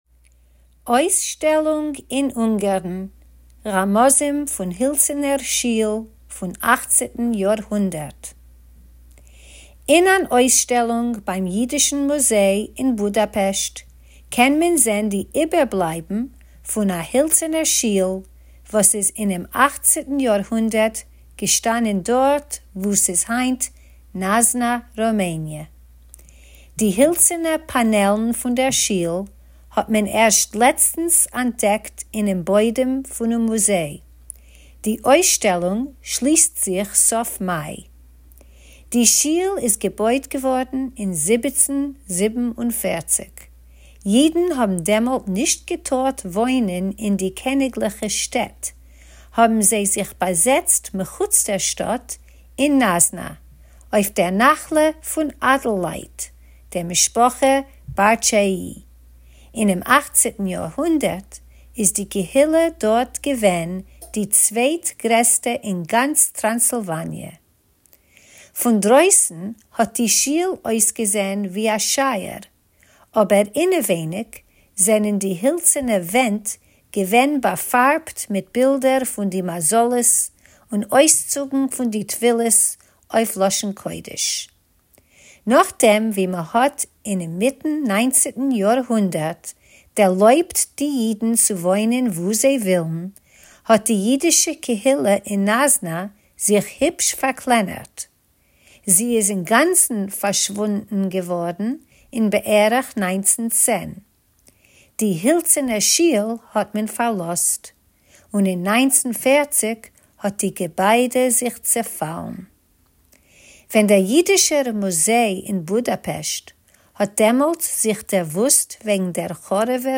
Tidbits is a bi-weekly feature of easy news briefs in Yiddish that you can listen to or read, or both!